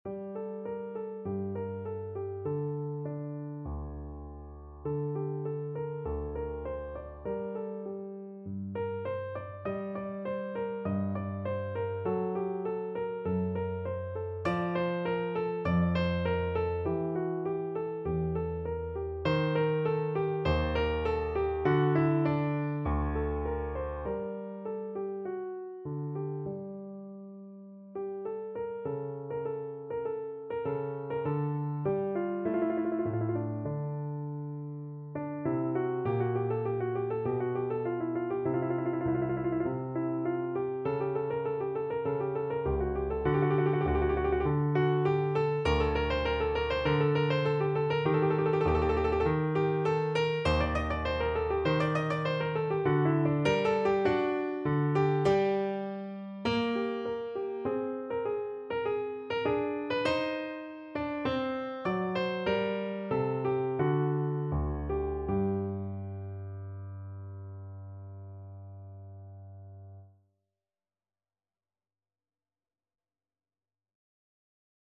No parts available for this pieces as it is for solo piano.
4/4 (View more 4/4 Music)
Largo
Classical (View more Classical Piano Music)